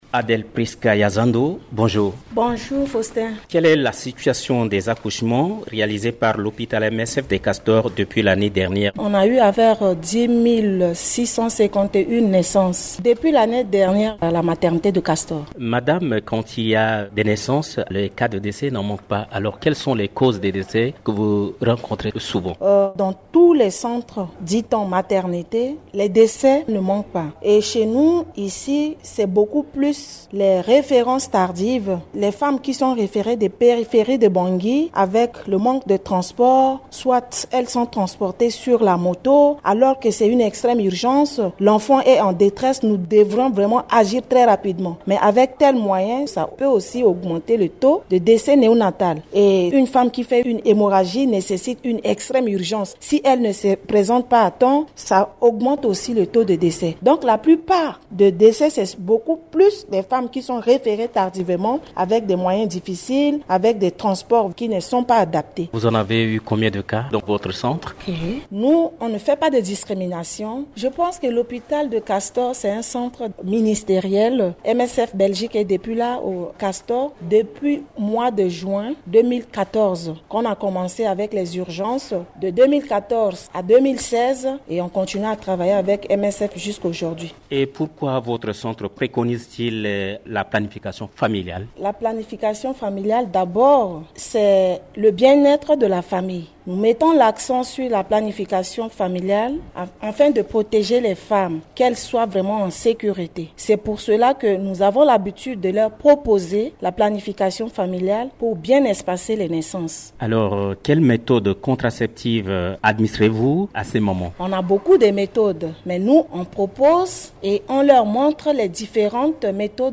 Dans une interview accordée à Radio Ndeke Luka